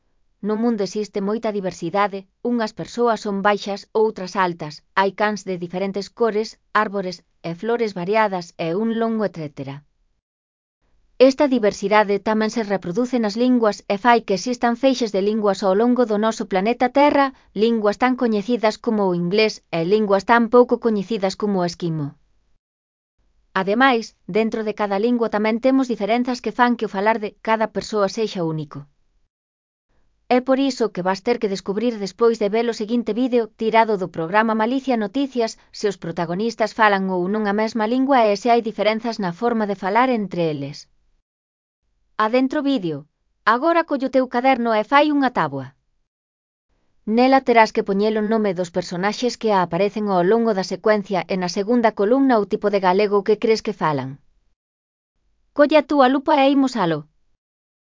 É por iso que vas ter que descubrir despois de ver o seguinte vídeo, tirado do programa Malicia Noticias, se os protagonistas falan ou non a mesma lingua e se hai diferenzas na forma de falar entre eles.
Despois de escoitares as diferentes maneiras de falar que ten a nosa lingua imos intentar clasificalas.